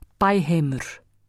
uttale